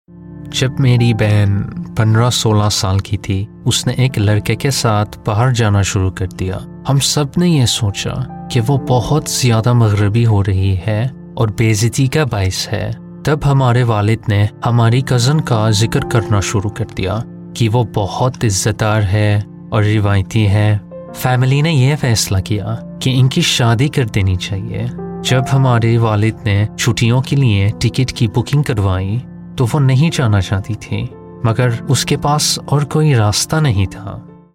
Hindi, Male, 20s-30s